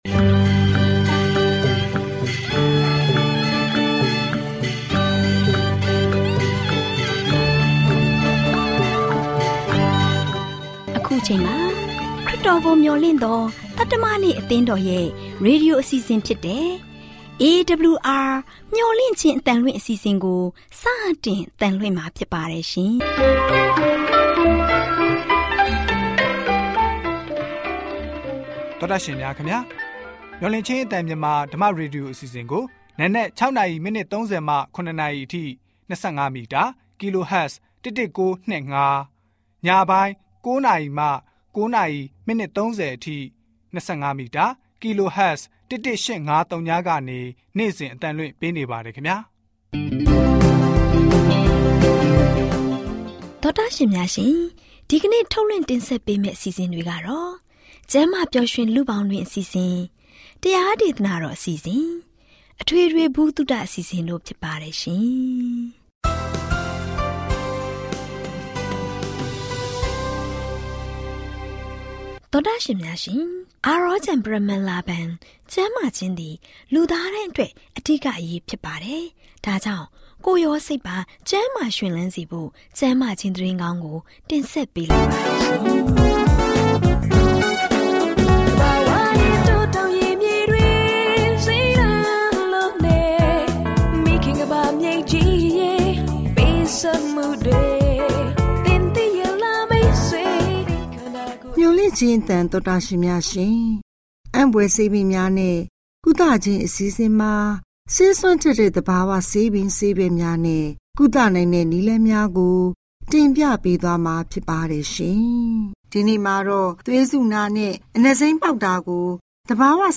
Burmese / မြန်မာစကား radio program for Myanmar provided by Adventist World Radio